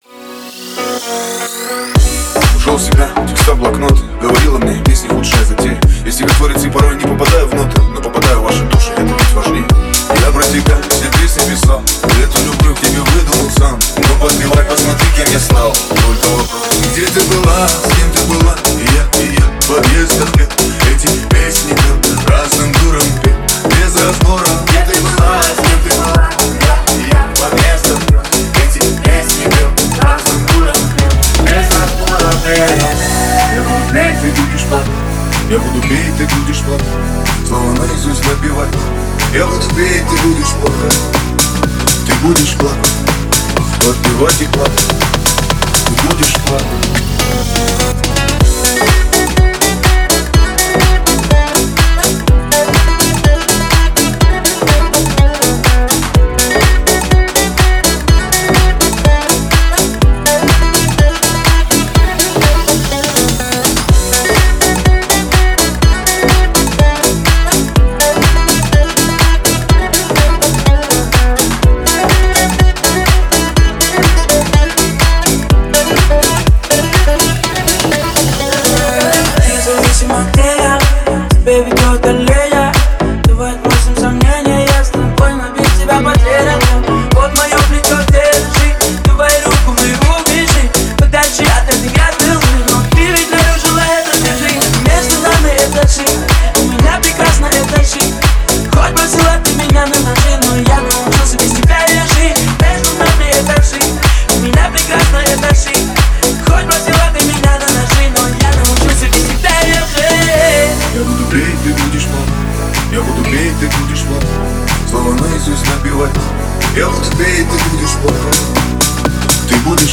это яркий трек в жанре хип-хоп и R&B